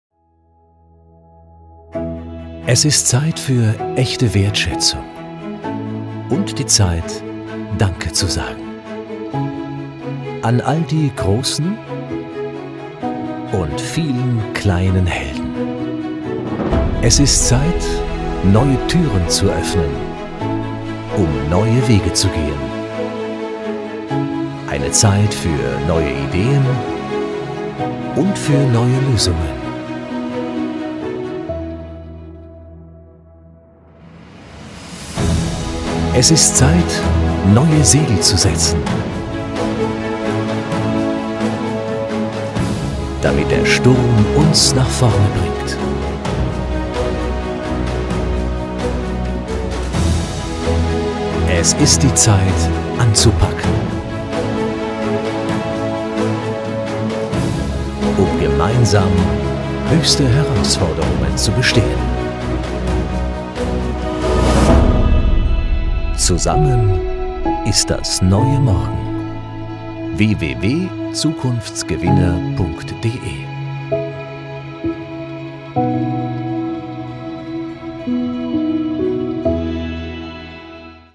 Professioneller Sprecher mit markanter, warmer, sympathischer Stimme im besten Alter + Homestudio
Sprechprobe: Industrie (Muttersprache):